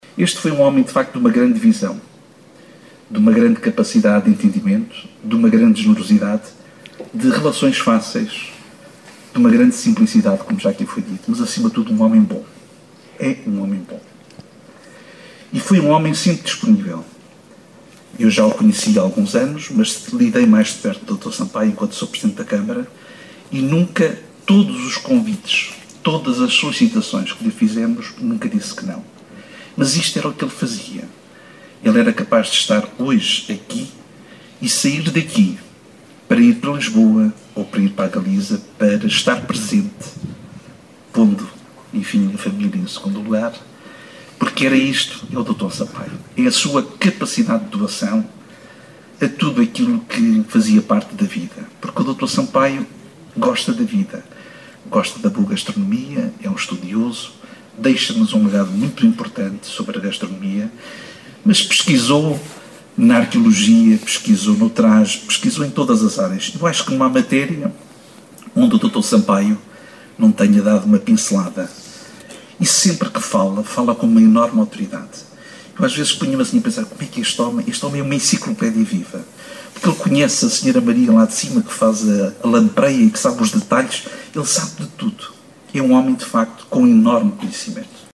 Família, amigos e admiradores marcaram presença na cerimónia que decorreu no Forte da Lagarteira. O presidente da Câmara de Viana do Castelo, José Maria Costa, falou de um homem com uma grande visão